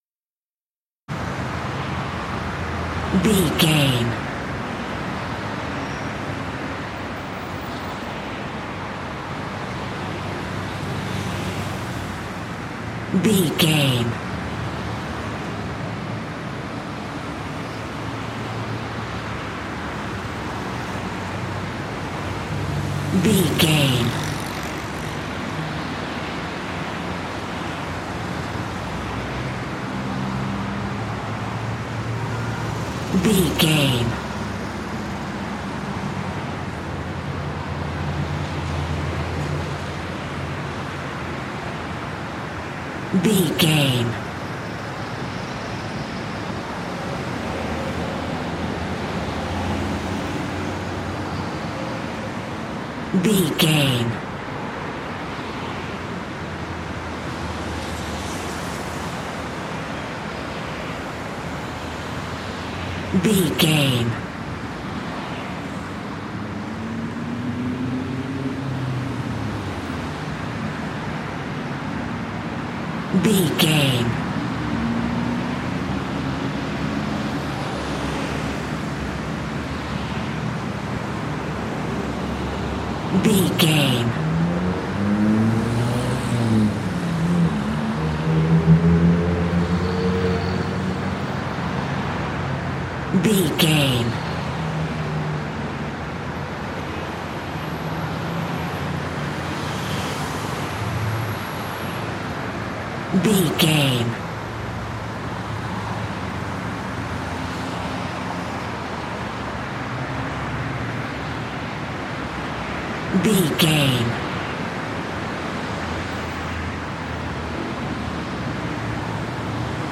Traffic highway
Sound Effects
urban
ambience